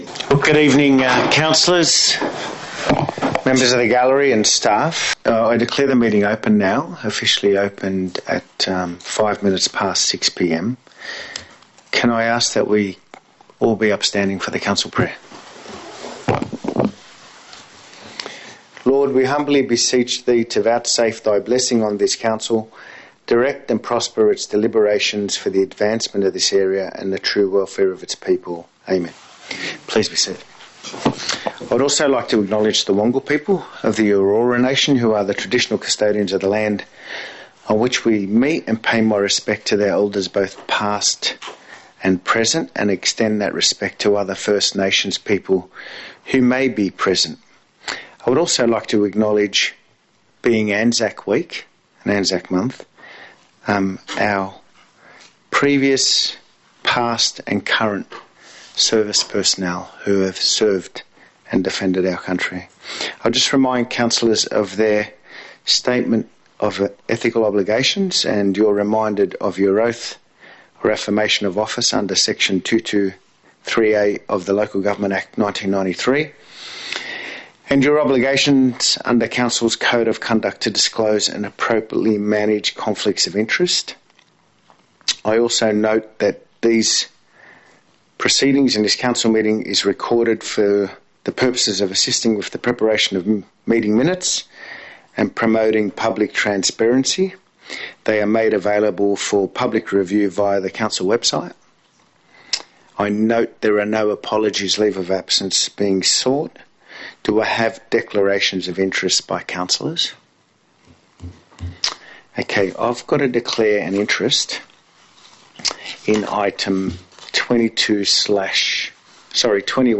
23 April 2024 Ordinary Council Meeting
Notice is hereby given that a meeting of the Council of Burwood will be held in the Conference Room, 2 Conder Street, Burwood on Tuesday 23 April 2024 at 6.00 pm to consider the matters contained in the attached Agenda
april-edited-audio-without-public-forum_1.mp3